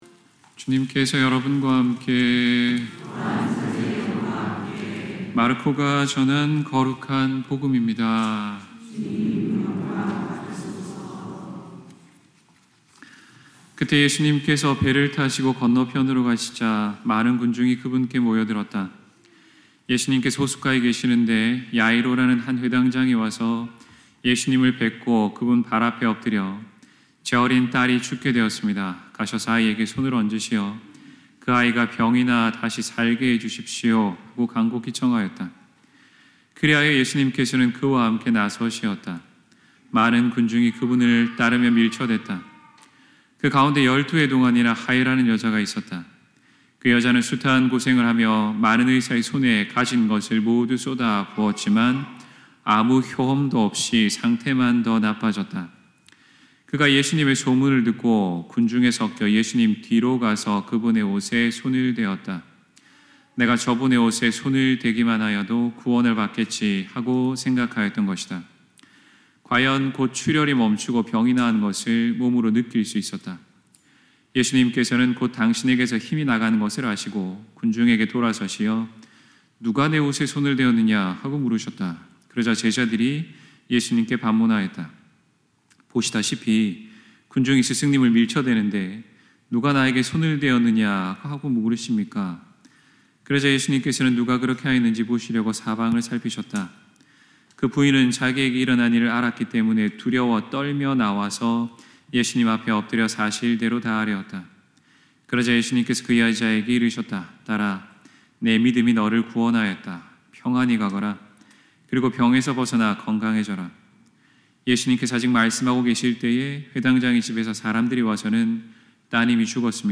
2024년 6월 30일 연중 제13주일 (교황 주일) 신부님 강론